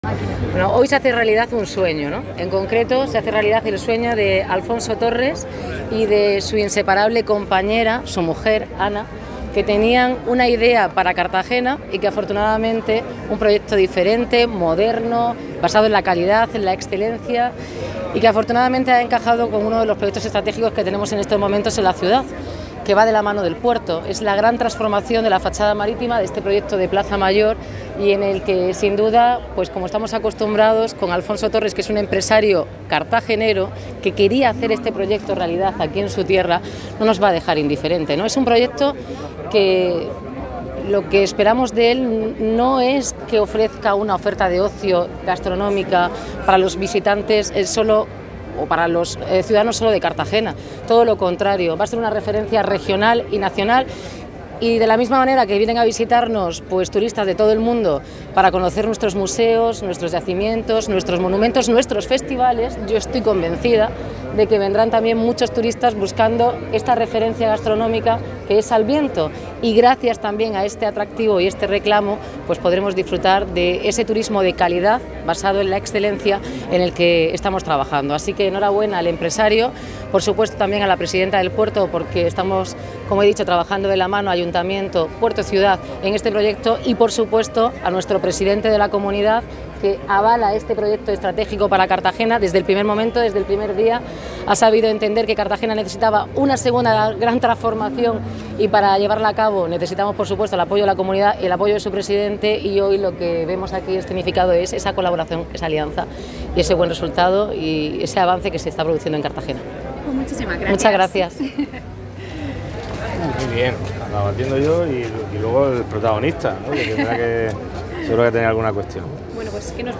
El presidente de la Región de Murcia, Fernando López Miras, declaró en el acto de inauguración : "Con Alviento se demuestra lo importante que son las personas valientes y empresarios que están dispuestas a dar un paso al frente, y con administraciones que facilitan la creación de oportunidades y creación de puestos de trabajo, y hacer más próspero el territorio.